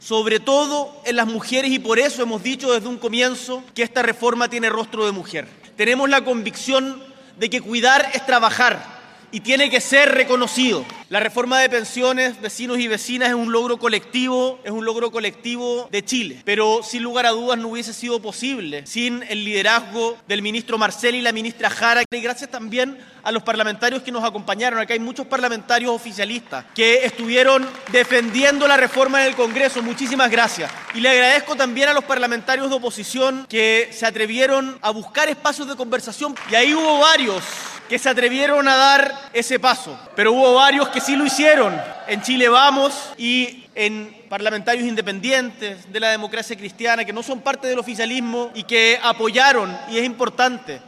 El Presidente Gabriel Boric encabezó la ceremonia de promulgación de la reforma de pensiones en el Centro Deportivo Cultural Chimkowe de Peñalolén, destacando que su aprobación representa un “logro colectivo de Chile”.